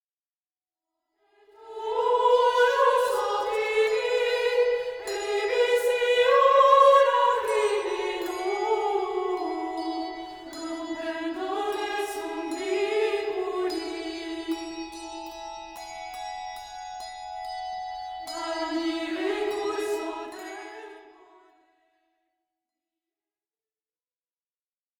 Hymne